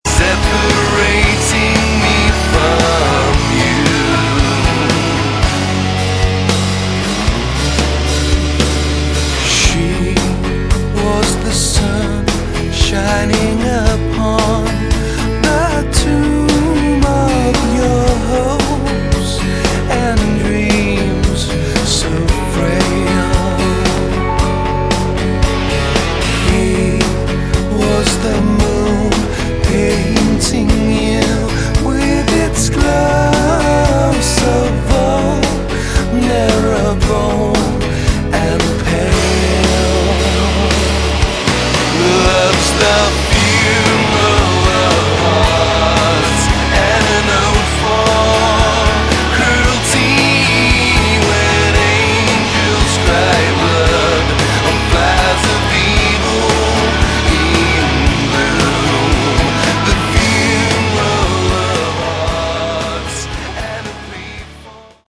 Category: Rock